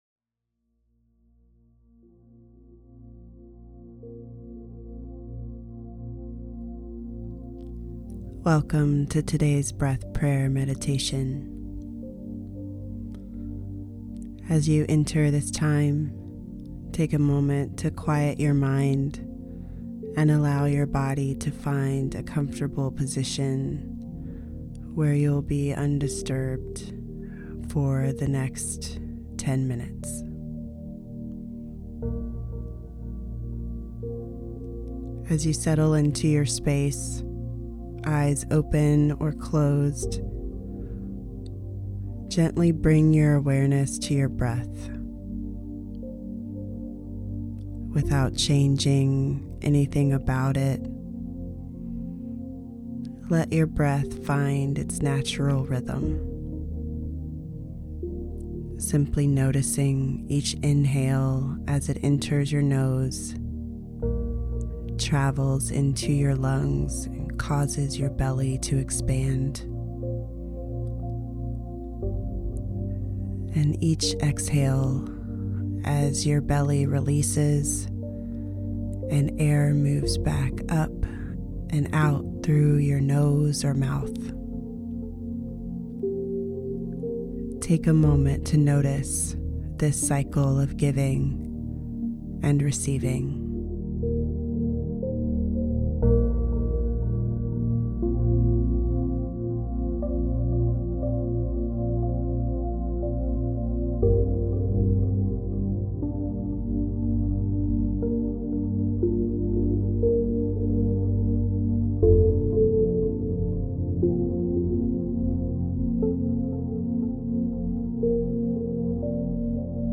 [Included is a 10-minute audio breath meditation, ending with breath prayer.]
Breath-Meditation.mp3